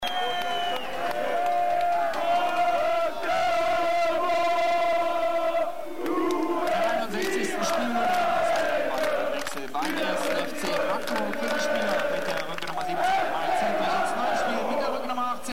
FCM-Fansongs und Blocklieder